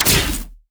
lightning-hit.ogg